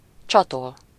Ääntäminen
IPA: [a.ta.ʃe]